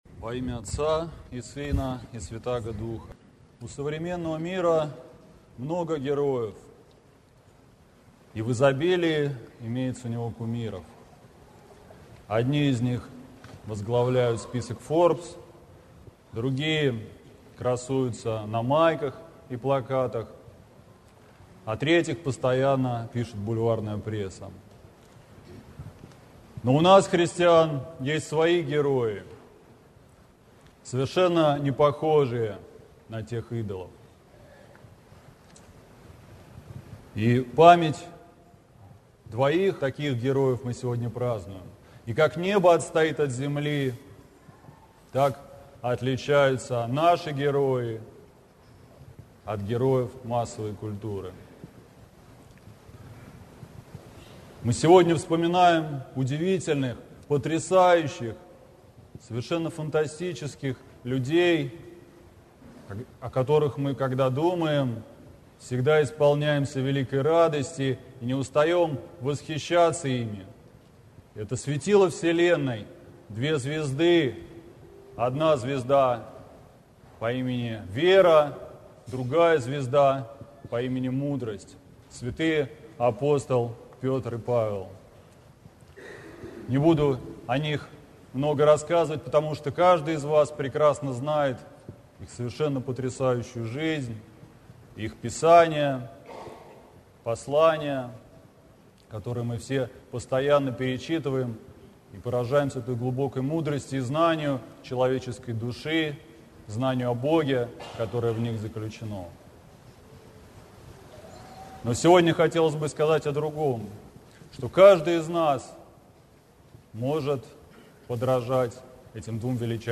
Слово в день памяти святых первоверховных апостолов Петра и Павла
Спаси Господи за назидательную проповедь!